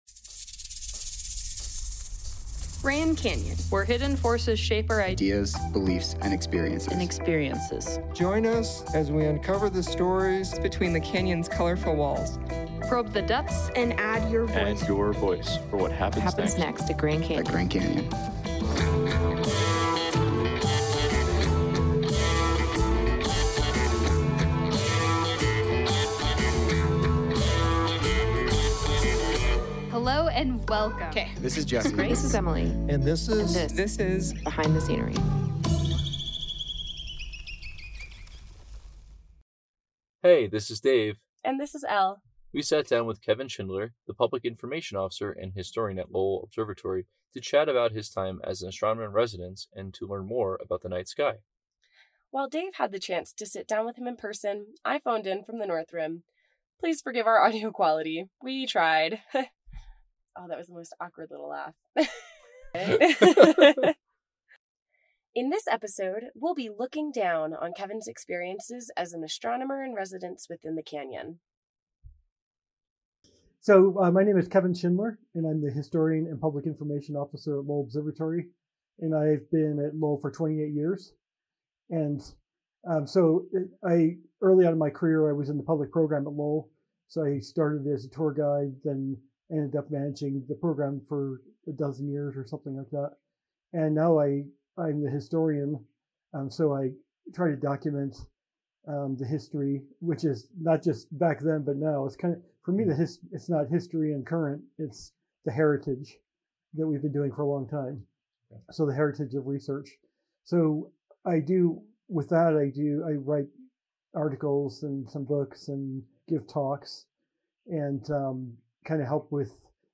Please forgive our audio quality, we tried.
*laughing*